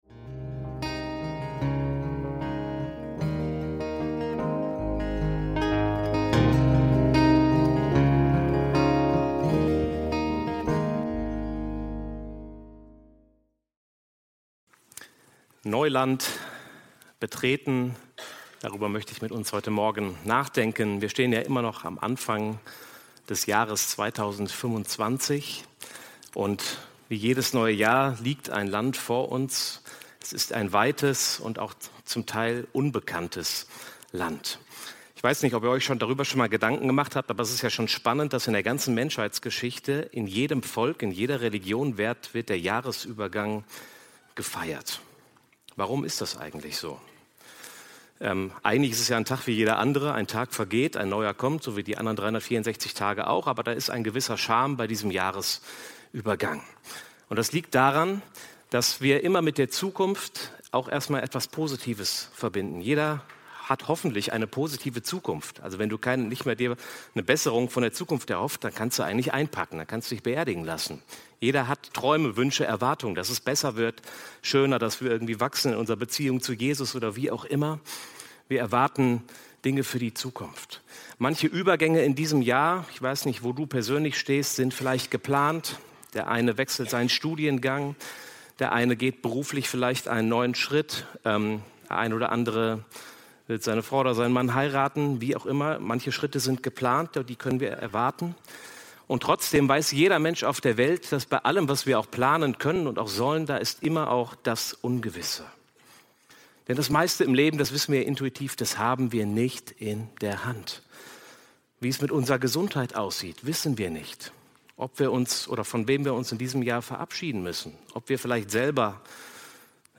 Neuland betreten – Predigt vom 12.01.2025 ~ FeG Bochum Predigt Podcast